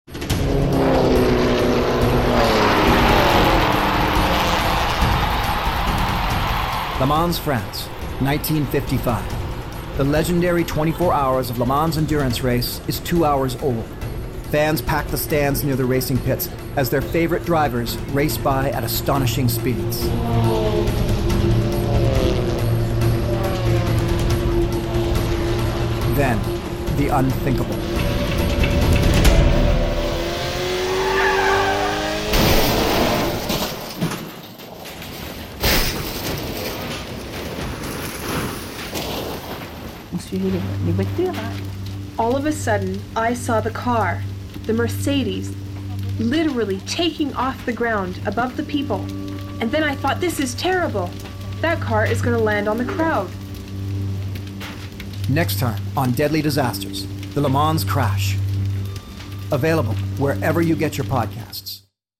Produced and Engineered in Dolby Atmos, Deadly Disasters' immersive sound-design brings history's worst disasters to vivid life in never-before-heard ways.
Hosted by Survivorman Les Stroud, and told through eye-witness interviews, expert testimonials, and meticulous historical research, Deadly Disasters opens an ear to the past like no one has done before.